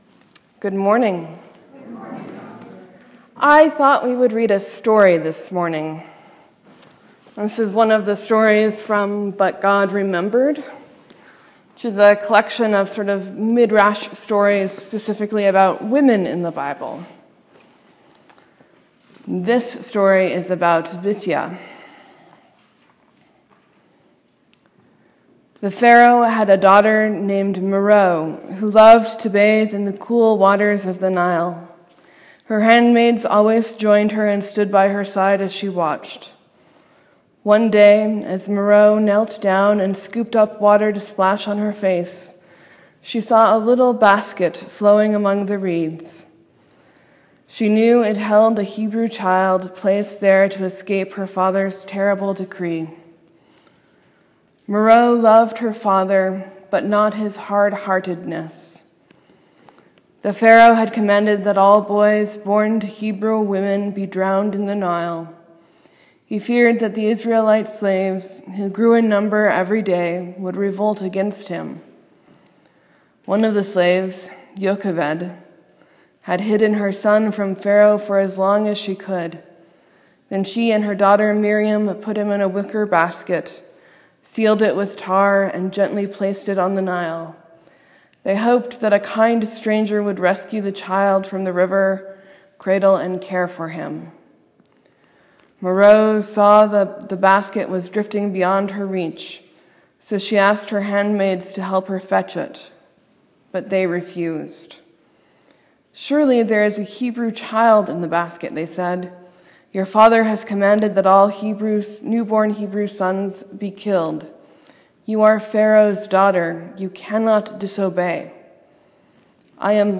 Sermon: This is the first parable of three that fit together.